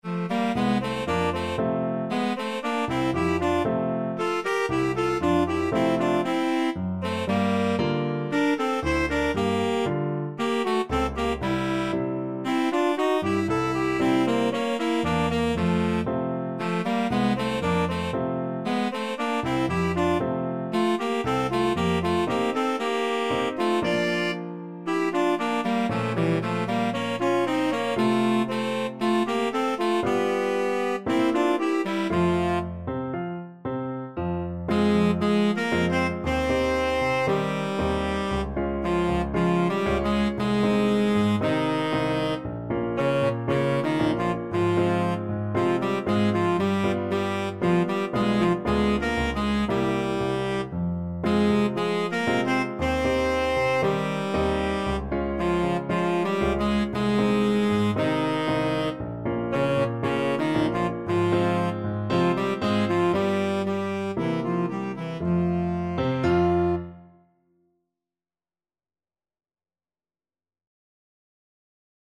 2/4 (View more 2/4 Music)
Tenor Sax Duet  (View more Advanced Tenor Sax Duet Music)